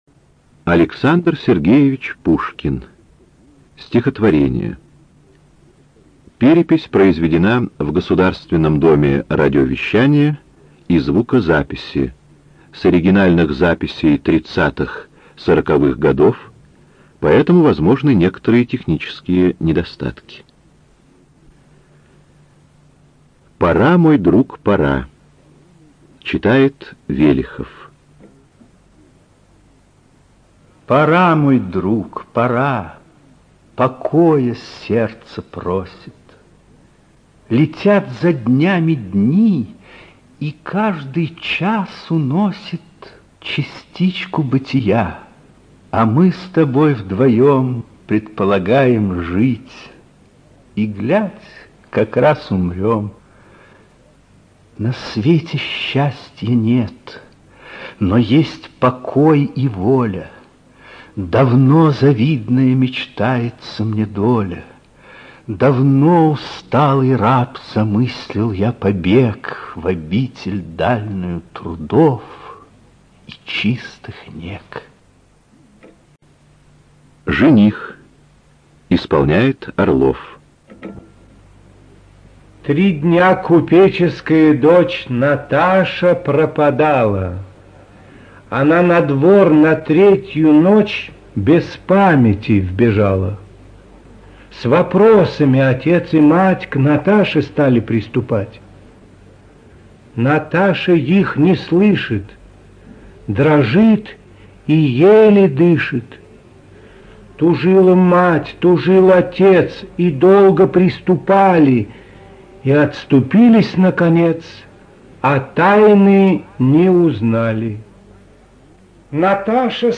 НазваниеСтихотворения. Записи тридцатых-сороковых годов двадцатого века
ЖанрПоэзия